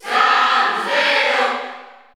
Zero_Suit_Samus_Cheer_Spanish_PAL_SSBU.ogg